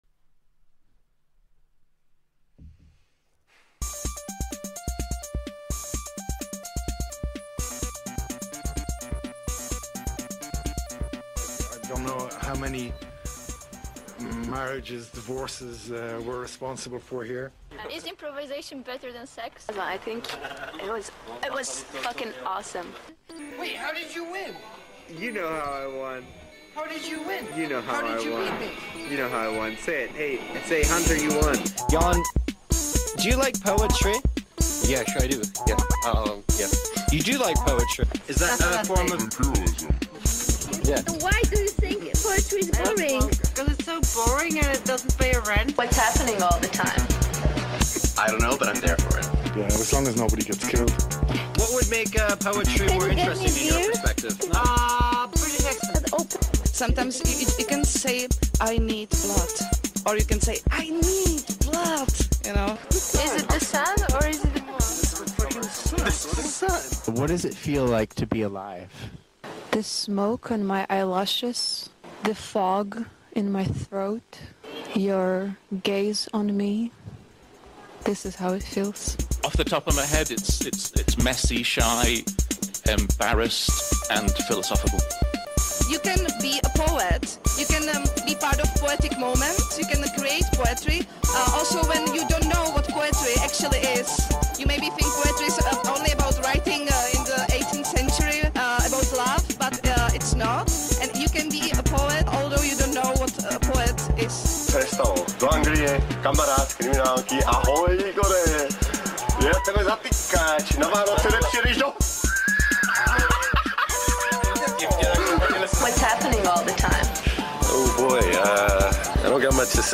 interview
music & sounds from Žižkov